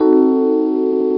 E Piano Dim Sound Effect
e-piano-dim.mp3